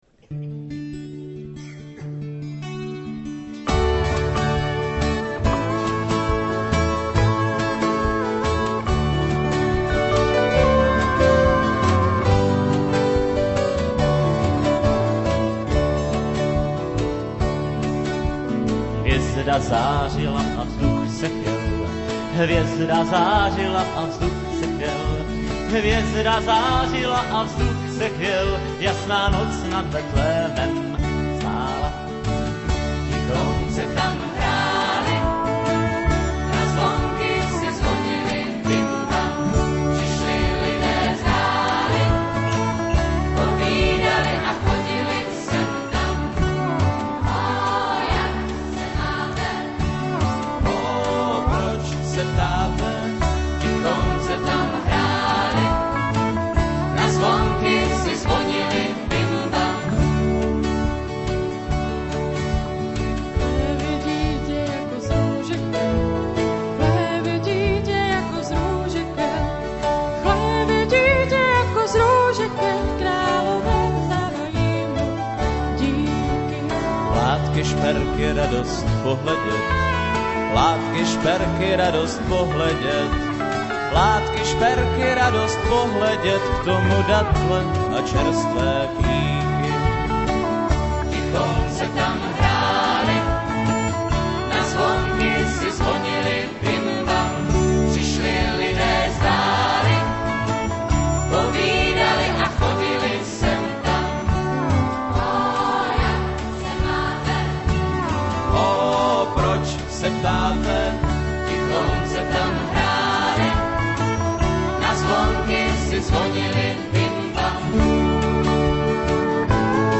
(spolu s pěveckými sbory vsetínského gymnázia)